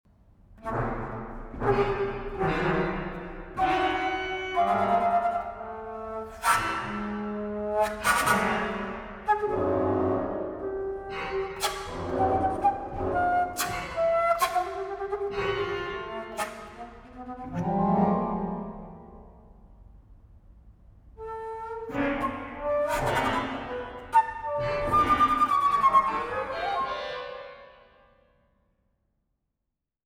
Neue Musik für Flöte und Orgel (II)
Flöte
Orgel